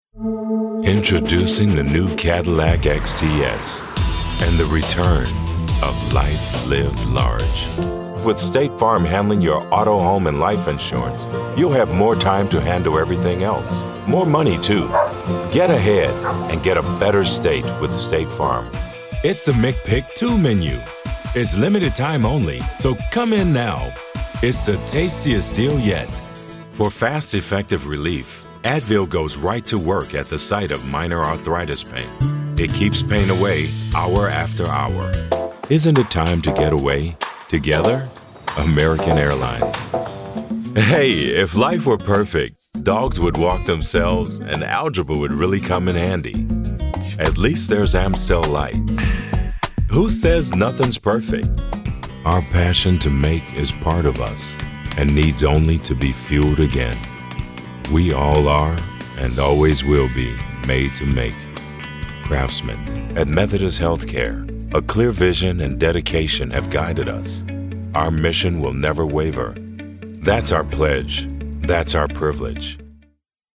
Domayne Australia (style hard sell) Voice Over Commercial Actor + Voice Over Jobs
Adult (30-50) | Older Sound (50+)